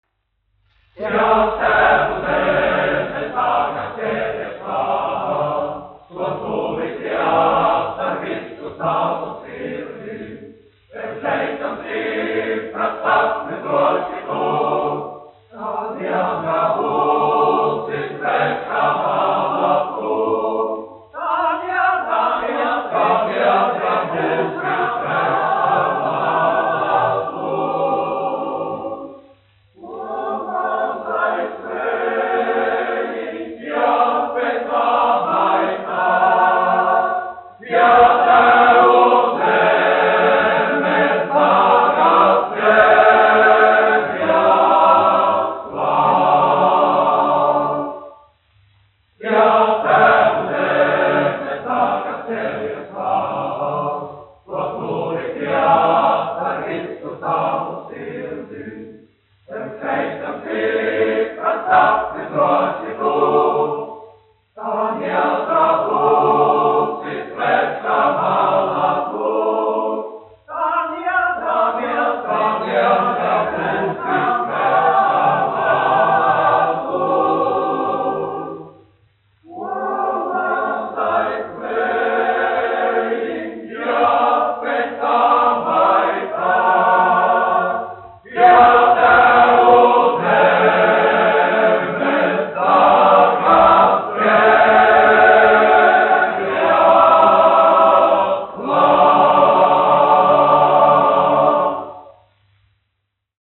Kalniņš, Teodors, 1890-1962, diriģents
Prezidiju konventa vīru koris, izpildītājs
1 skpl. : analogs, 78 apgr/min, mono ; 25 cm
Kori (vīru)
Skaņuplate